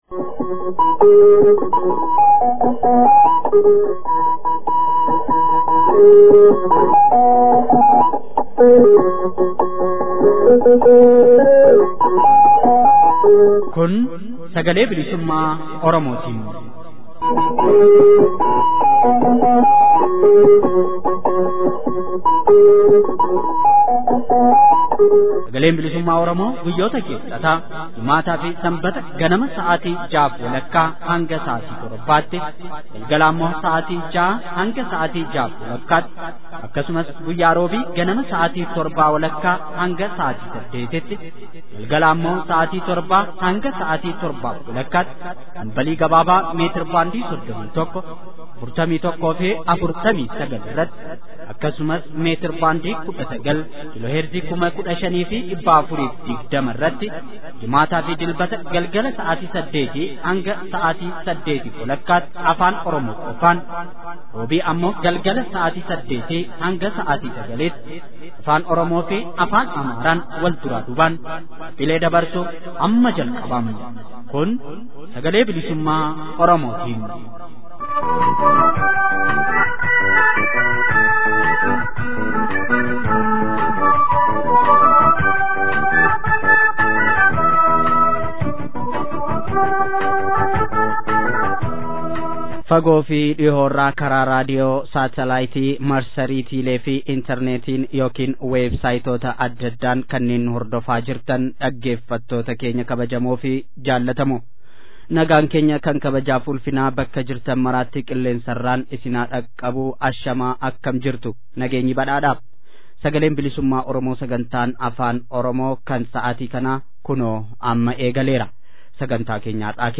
SBO: Muddee 04 bara 2016. Oduu, Gabaasa haala Warraaqsa FXG irratti qindaa’e fi Qophii olola ergamaan wayyaanee LAMMAA MAGARSAA lallabaa jirurratti xiyyeeffatu.